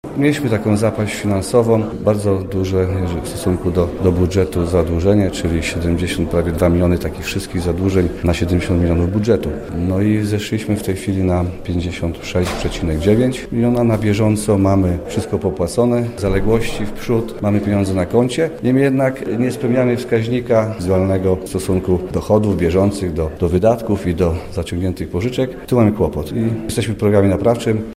Janusz Dudojś, burmistrz Lubska, mówi, że sytuacja powoli polepsza się, a zadłużenie maleje: